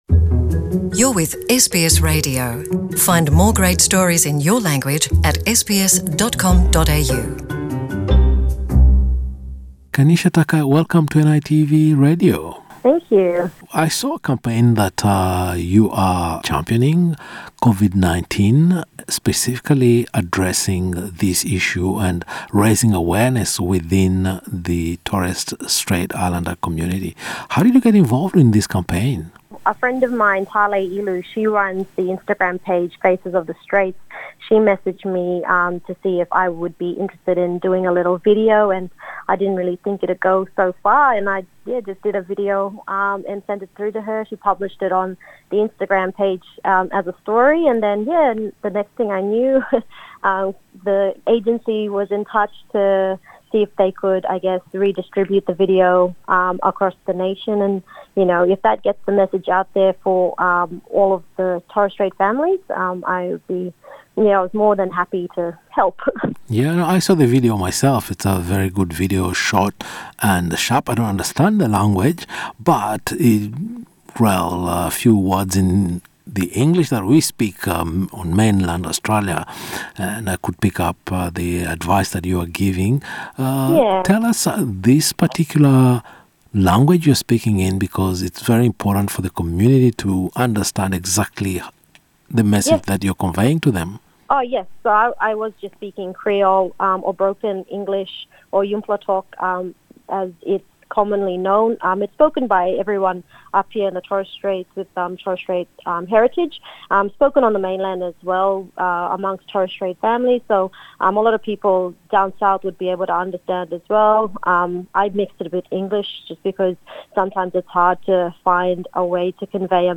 In an interview with NITV Radio